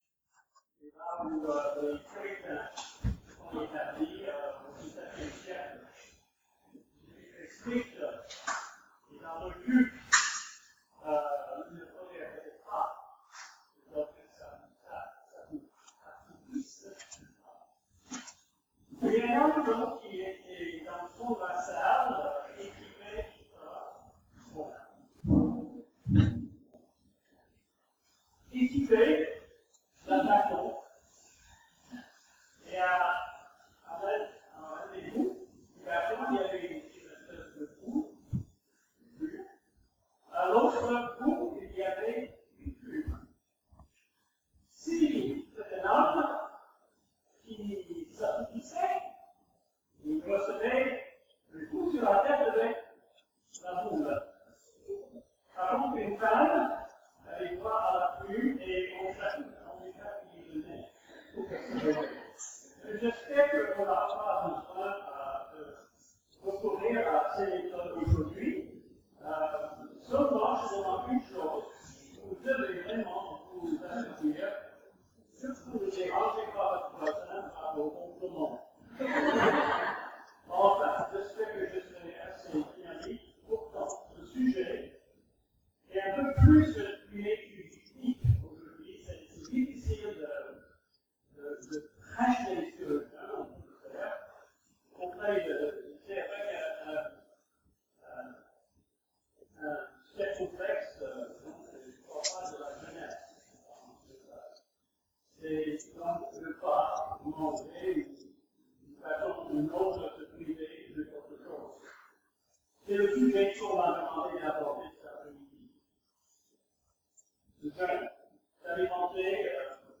(Désolé pour la qualité audio)